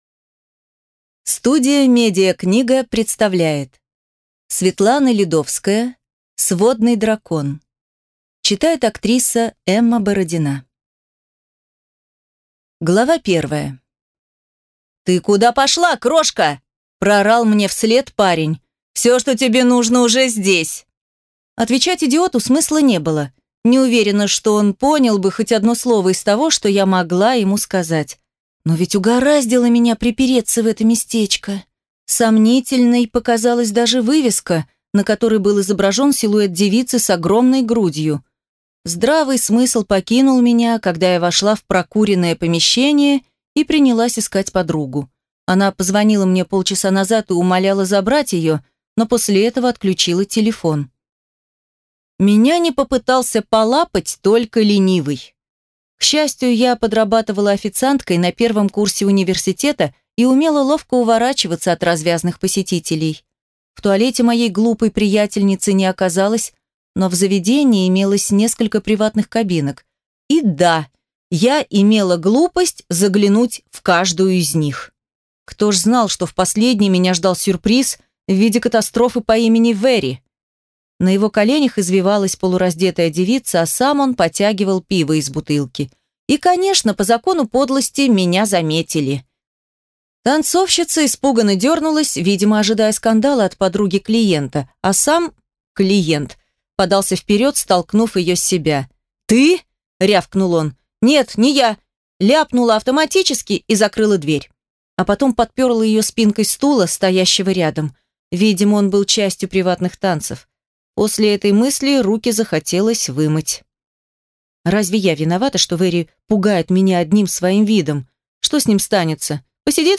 Аудиокнига Сводный дракон | Библиотека аудиокниг